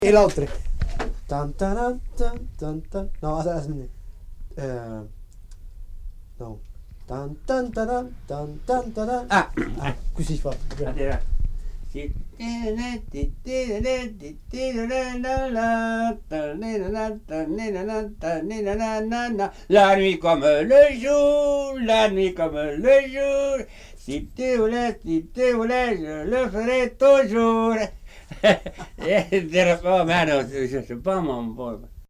Aire culturelle : Lauragais
Lieu : Villaudric
Genre : chant
Effectif : 1
Type de voix : voix d'homme
Production du son : fredonné
Danse : varsovienne